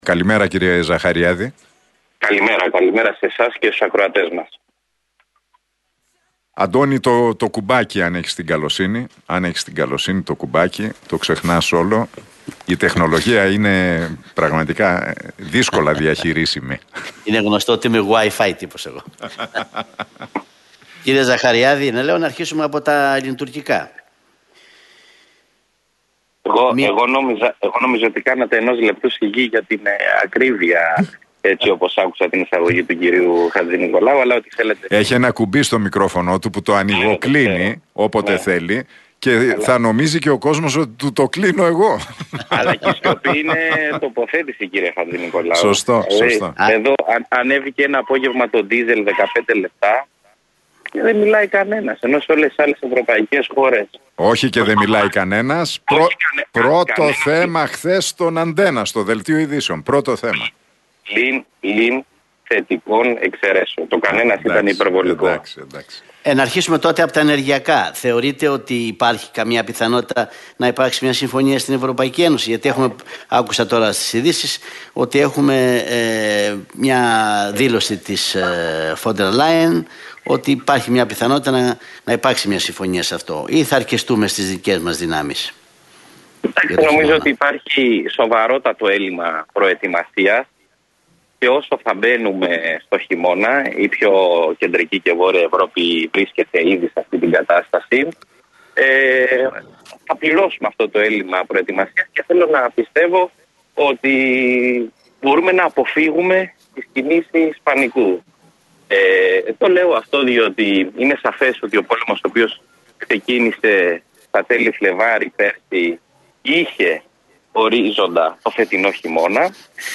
σε συνέντευξή του